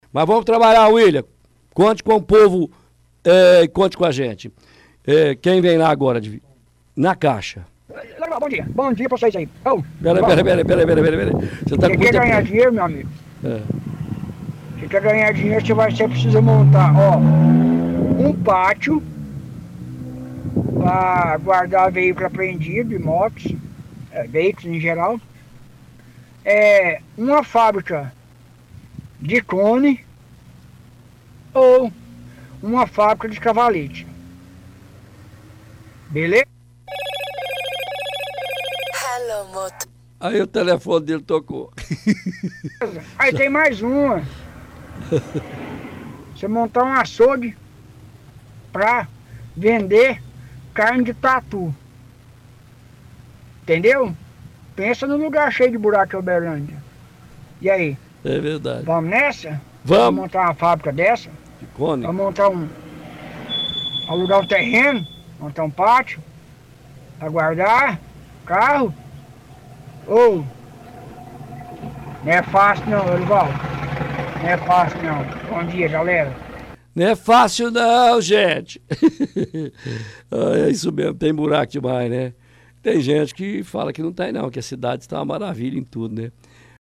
– Ouvinte faz piada reclama de buracos na cidade de Uberlândia.